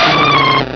sovereignx/sound/direct_sound_samples/cries/delibird.aif at master